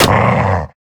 Minecraft Version Minecraft Version 25w18a Latest Release | Latest Snapshot 25w18a / assets / minecraft / sounds / mob / wolf / big / hurt3.ogg Compare With Compare With Latest Release | Latest Snapshot
hurt3.ogg